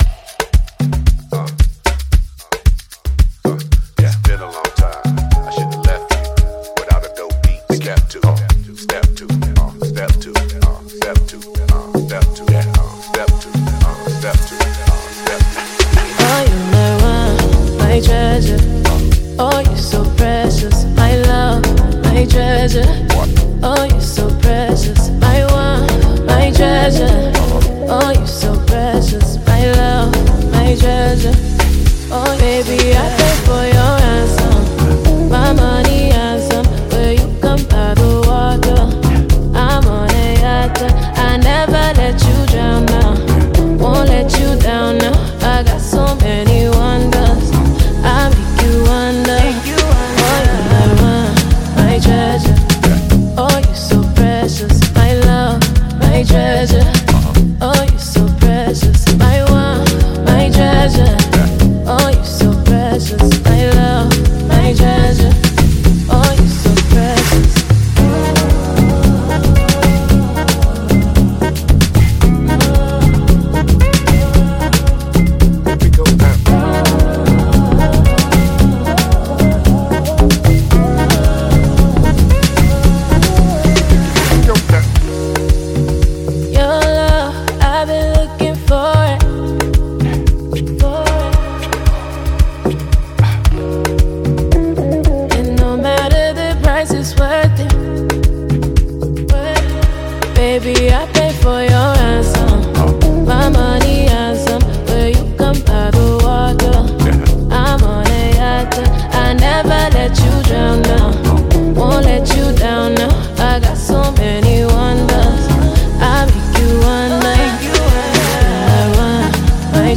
Packed with soulful melodies and compelling beats
Afrobeats